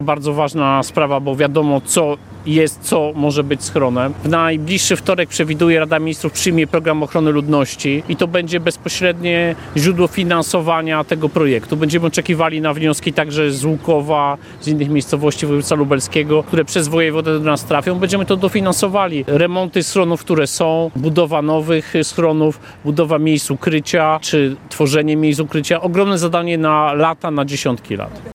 O ważnym zadaniu, jakim jest budowa schronów, mówił w Łukowie minister spraw wewnętrznych i administracji Tomasz Siemoniak.